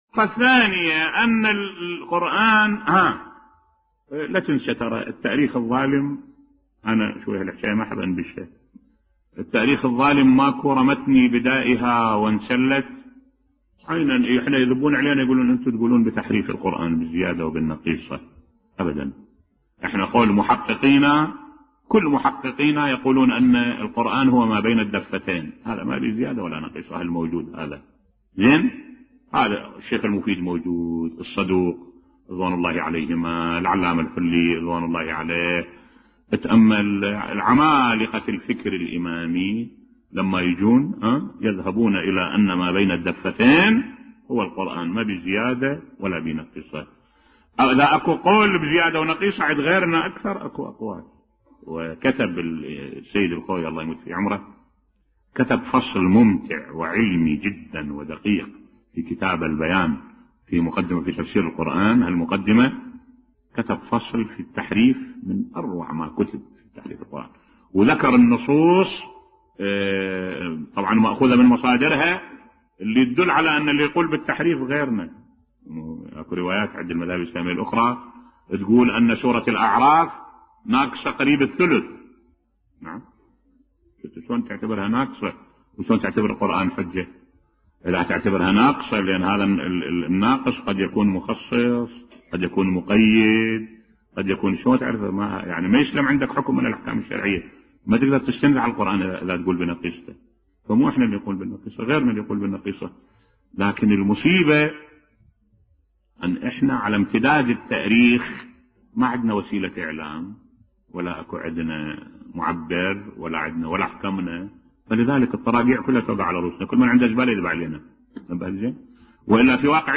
ملف صوتی تحريف القرآن عند غيرنا بصوت الشيخ الدكتور أحمد الوائلي